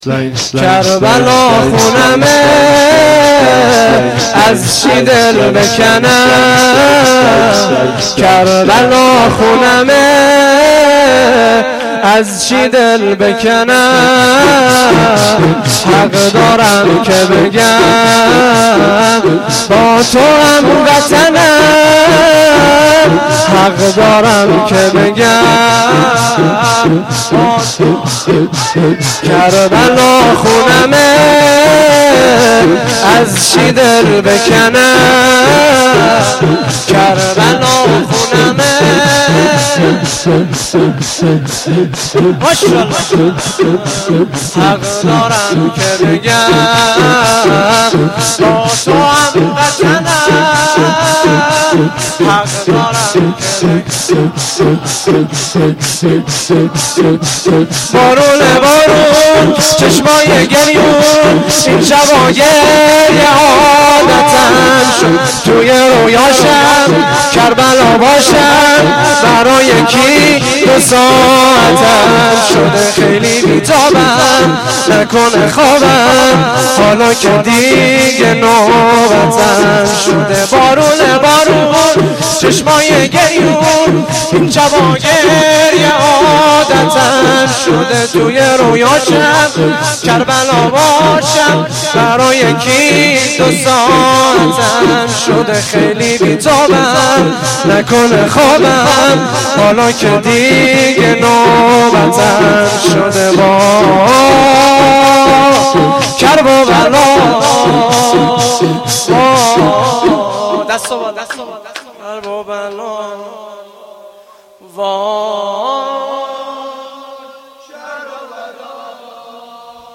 شور
روضه
shoor2.mp3